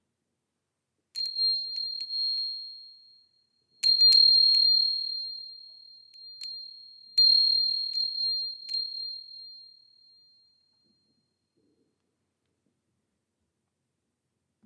Japanese Teahouse Wind Chime made from cast iron
Teahouse Wind Chime
Perfect for garden, patio, or porch, the gentle chime of this bell brings a sense of serenity, helping you connect with the present moment.
Suspended from a teahouse frame, the moss green bell is shaped after the iconic temple bells of Japan.
Teahouse-Windchime.mp3